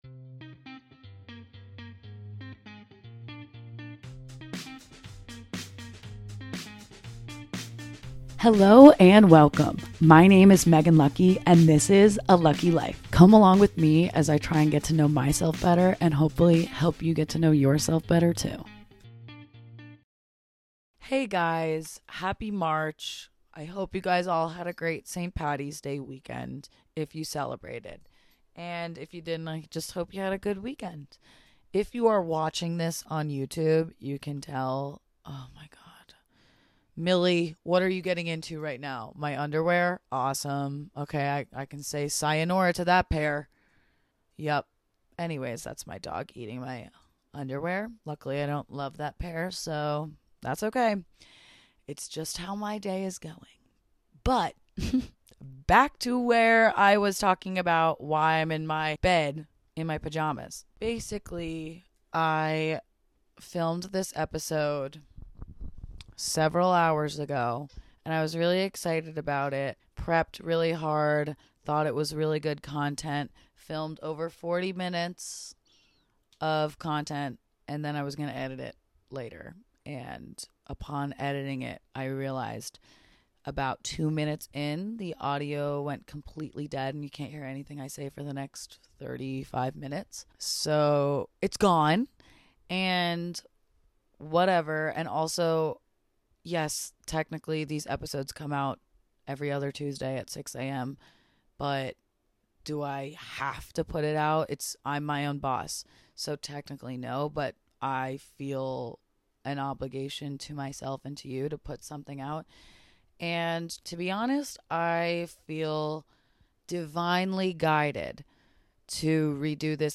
This week my planned episode got foiled by an audio failure so I pivoted and decided to talk about the real hard stuff I'm feeling instead in my pajamas on my bed after a hard day/week/month.